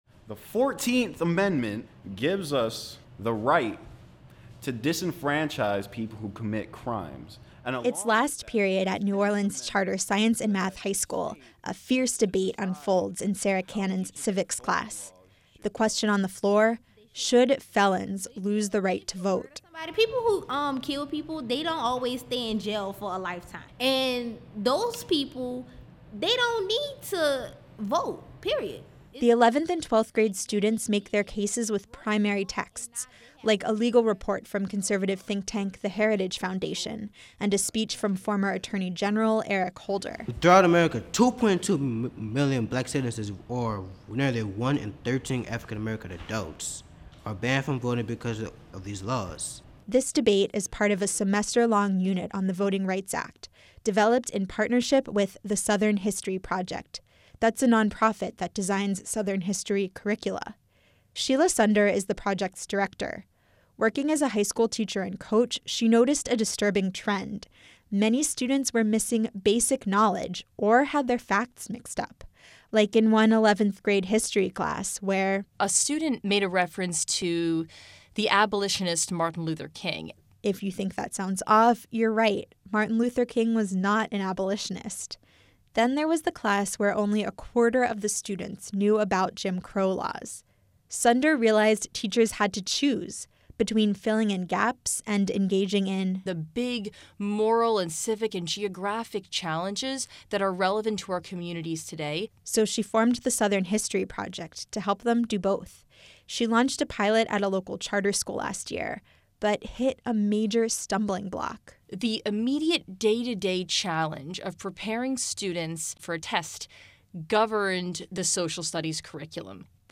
“People who kill people don’t always stay in jail for a lifetime. And those people, they don’t need to vote. Period. They broke the law and now they have to take their lick for it. It’s the consequences,” one student said.